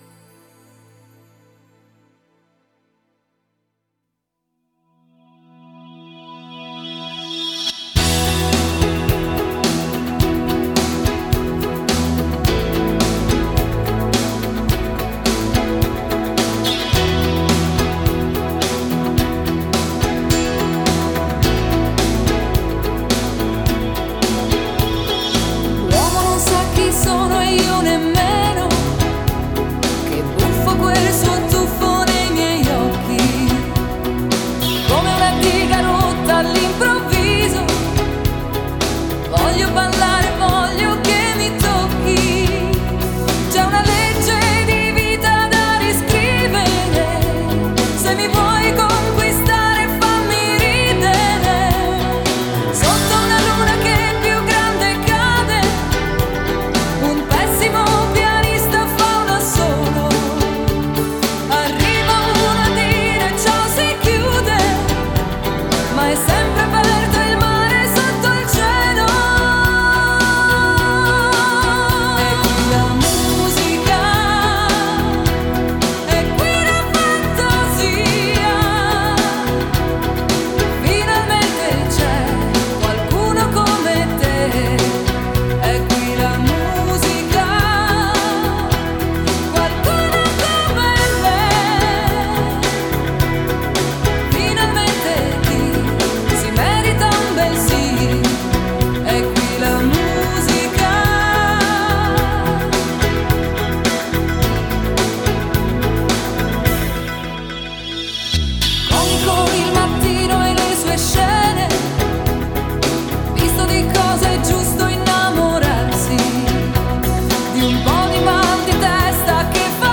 Жанр: Italo Pop